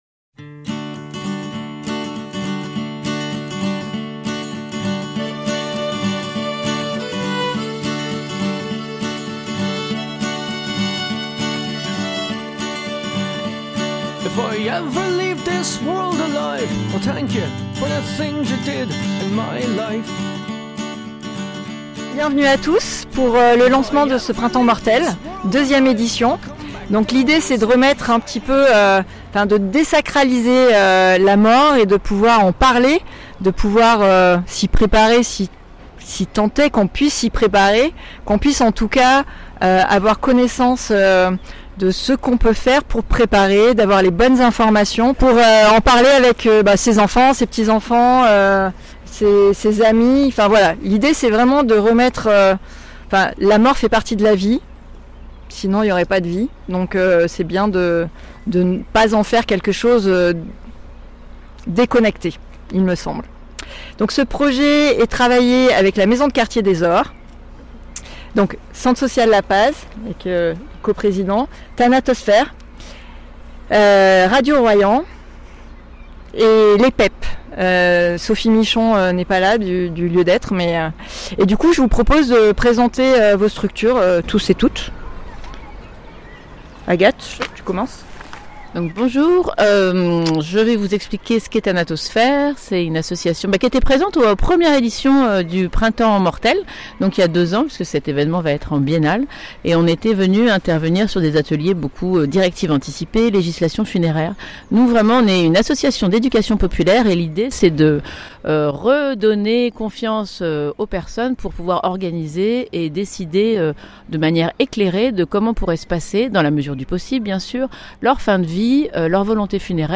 Sortir la mort du tabou, et en parler ensemble de manière sérieuse mais légère… c’est le principe du Printemps Mortel. L’ouverture de l’édition 2025 du festival s’est déroulée le vendredi 18 avril lors d’une conférence de presse à Saint Nazaire en Royans. Les organisateurs se sont succédés au micro pour présenter l’évènement, qui mêle conférences, ateliers informatifs, ludiques ou créatifs, spectacles, cafés mortels, radio, soirée débat, banquet et blind test musical, jusqu’au 21 juin (retrouvez ICI le programme complet).